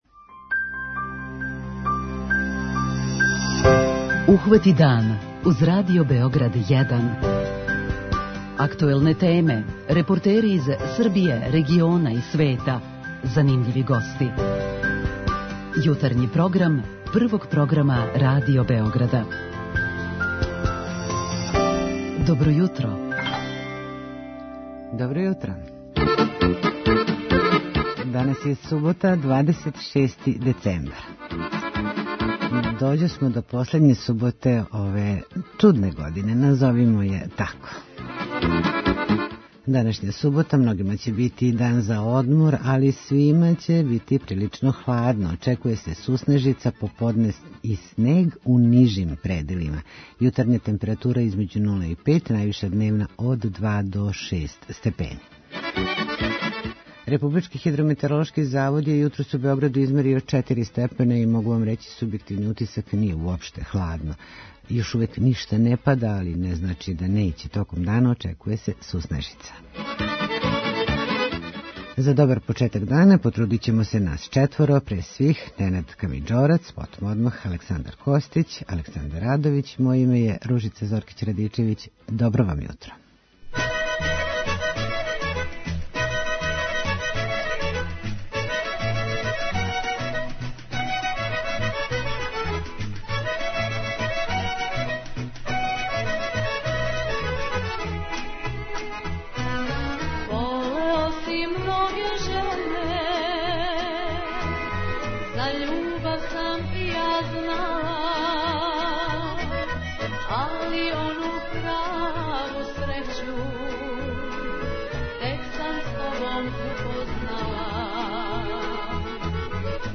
Џез фестивал у Ваљеву постоји већ 35 година, и одржаван је сваке године, без обзира на услове, чак и за време бомбардовања. Биће одржан и ове године, упркос пандемији а захваљујући упорности и технологији. Разговарамо тим поводом